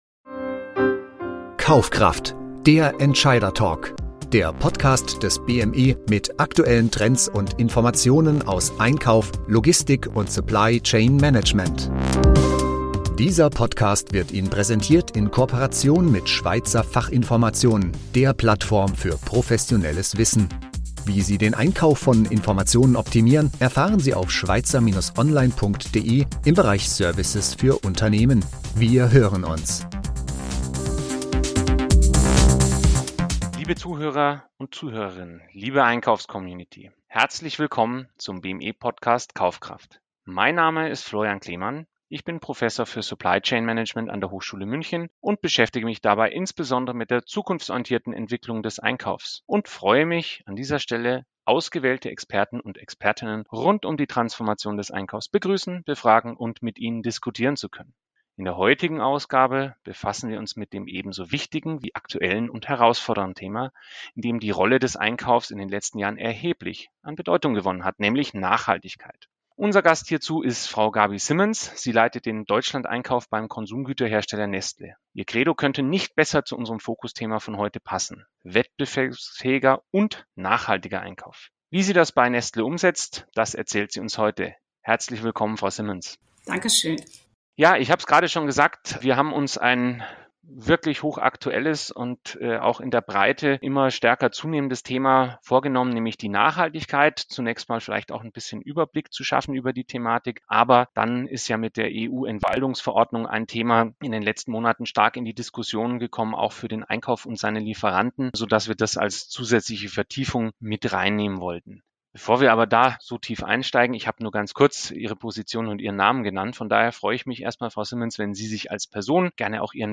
als Interviewpartnerin begrüßen zu dürfen.